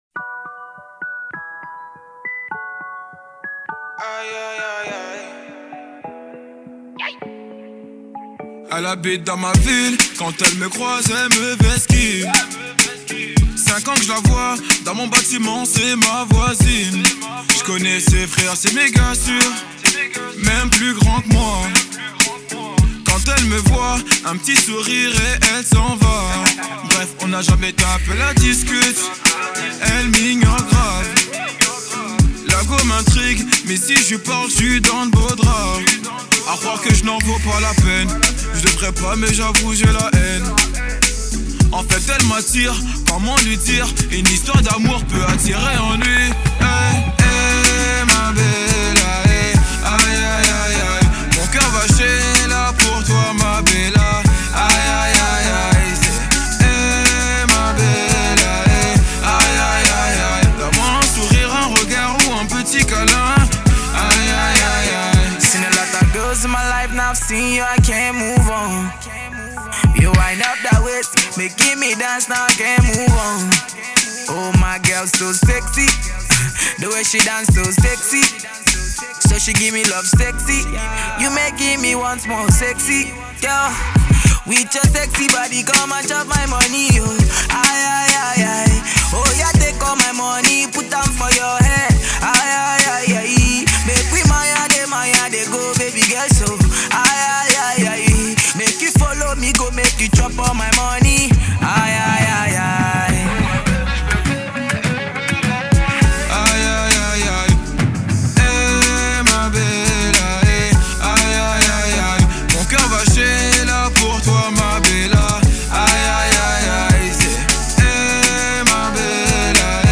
Afro Trap